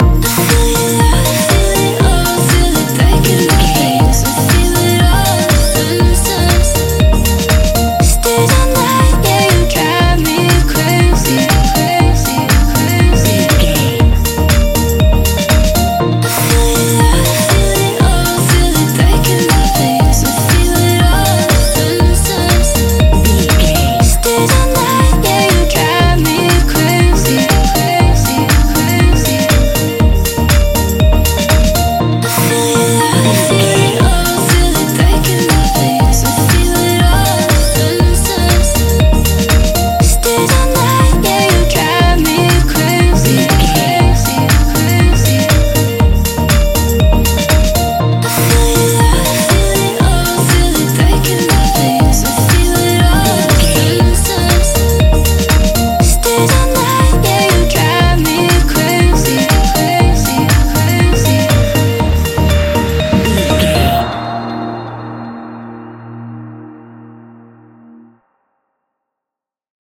Ionian/Major
D♯
house
electro dance
synths
techno
trance